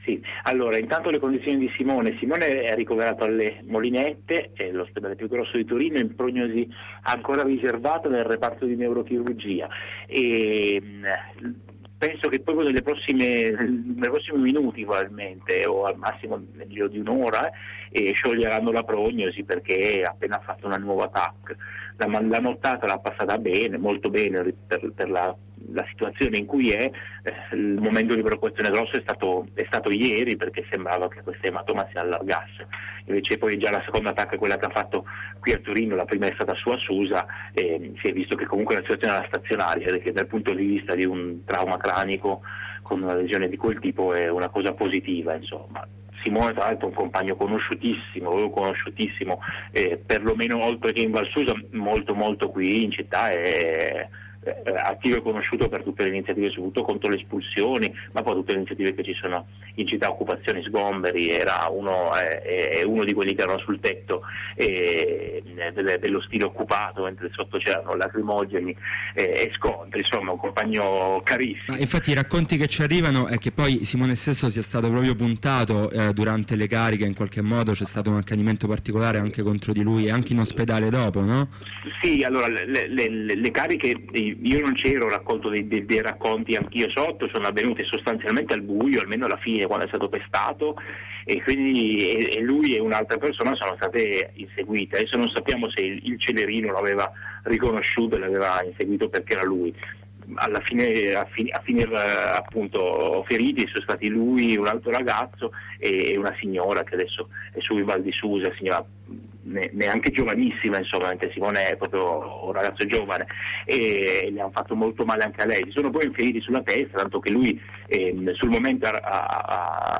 Ascolta il racconto di un recluso Ascolta || Leggi Tentata evasione e botte nel Cie di Torino Apr 27, 2010 Un nuovo, grosso, tentativo di evasione dal Cie di corso Brunelleschi ieri sera.